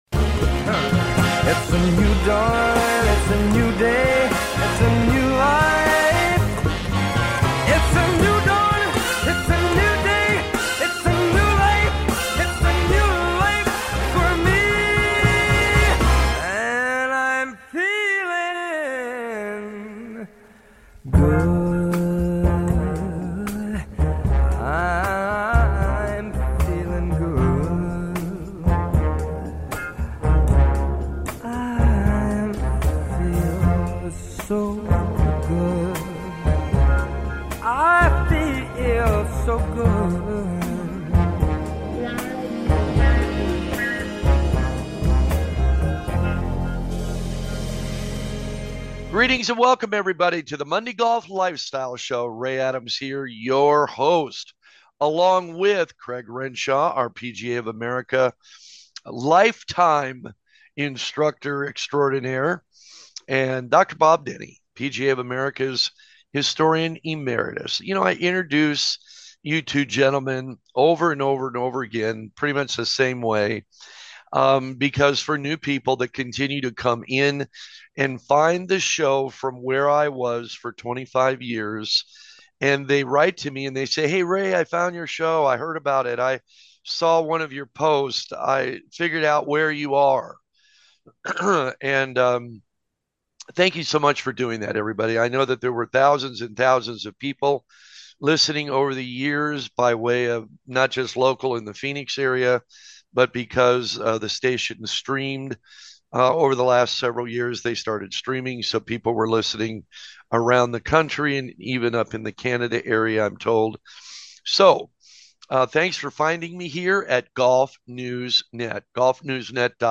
This golf talk show features great co-hosts, players and leading golf industry guests.